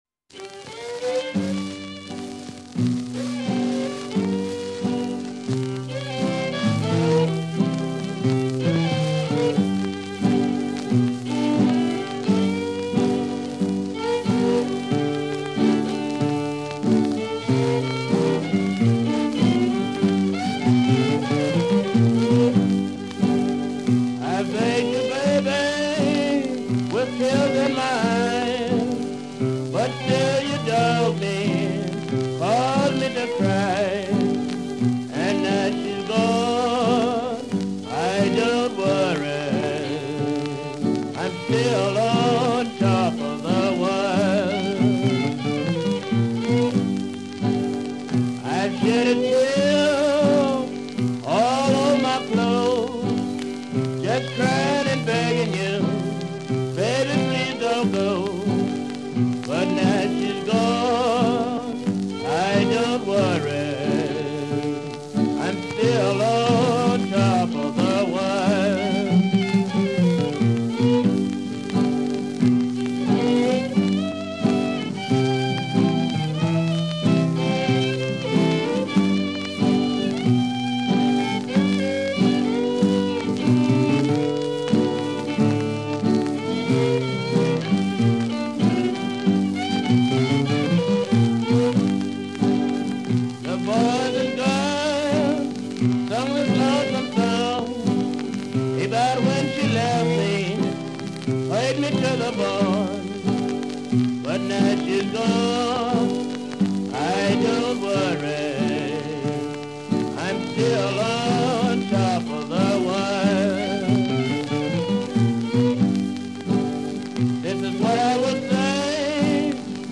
14 tracks from one of the earliest Blues bands.